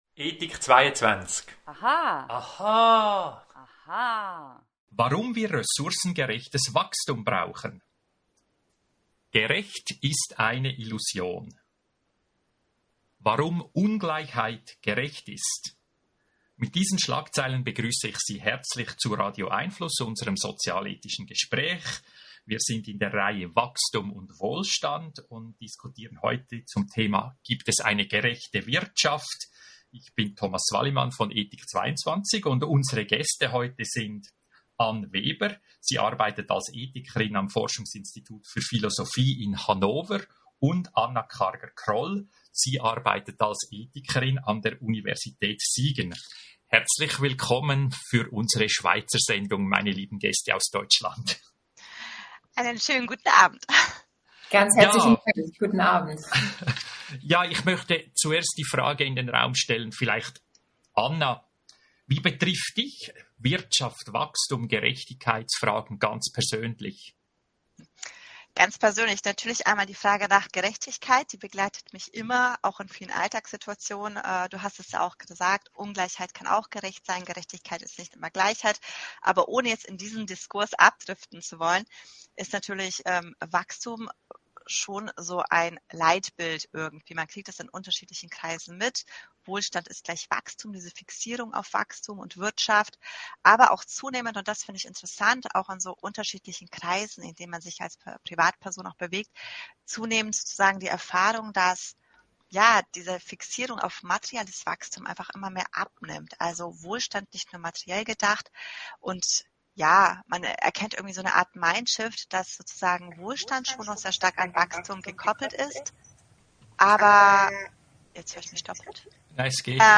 Radio🎙einFluss Podcasts hören Bleiben Sie über die kommenden Radio🎙einFluss Audio-Gespräche informiert!